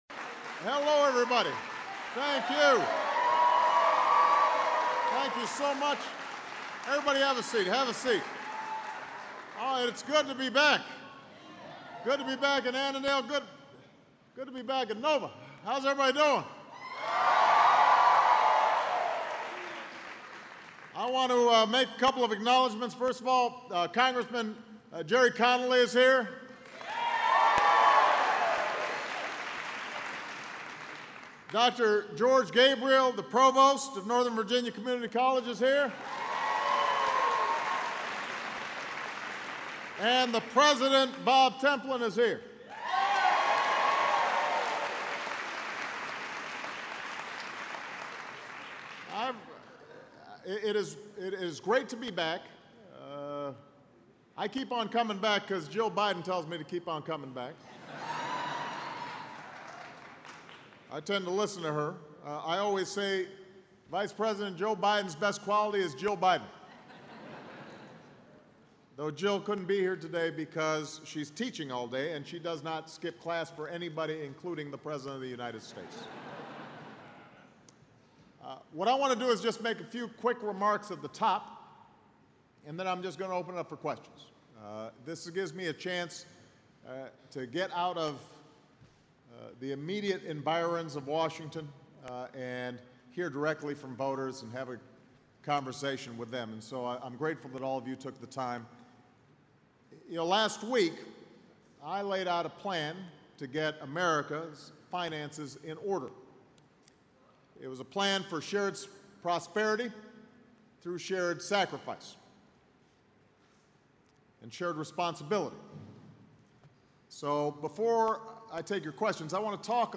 U.S. President Barack Obama speaks at a town hall event held at Norhtern Virginia Community College, Annandale, VA
President Barack Obama speaks about fiscal policy, the Federal budget, reducing the deficit, and improving America’s fiscal future at a town hall event held at Northern Virginia Community College in Annandale, VA. Obama declares the debate is not about whether the budget is cut, but how the budget is cut. Obama answers questions.